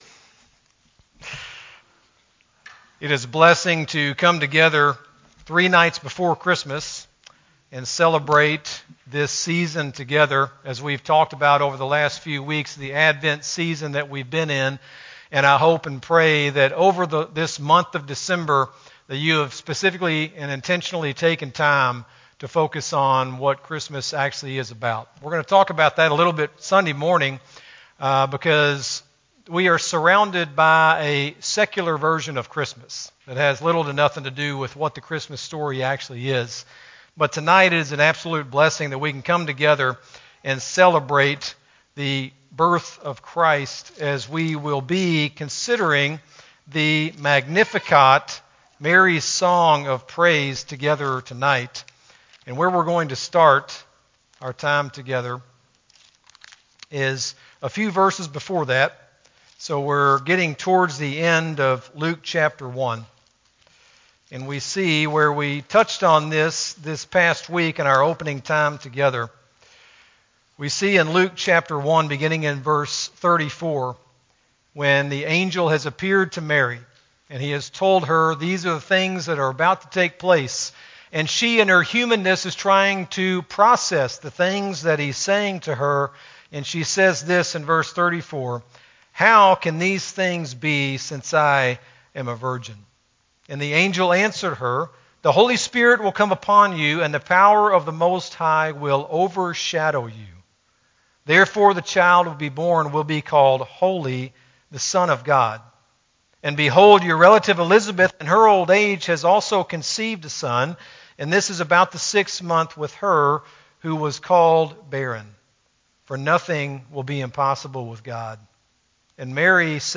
Christmas Eve Eve Eve Service: The Magnificat, Luke 1:46-56